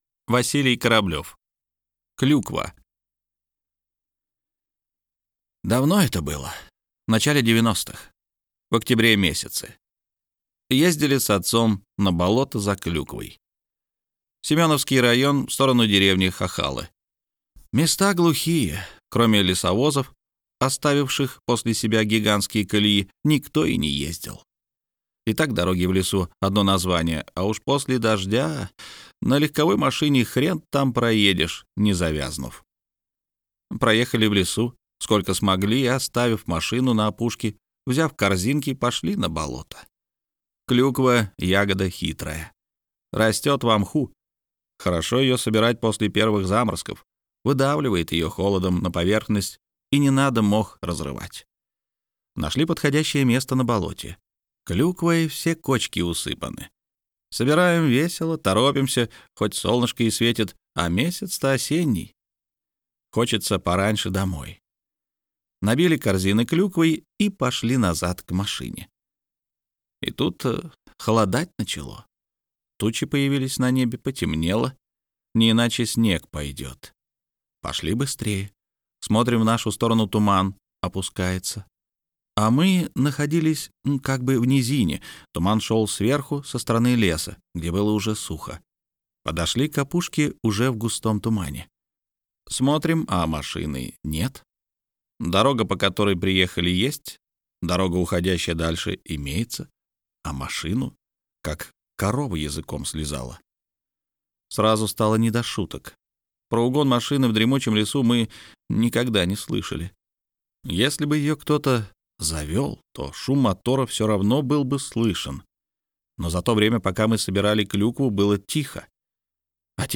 Аудиокнига Никто и никогда | Библиотека аудиокниг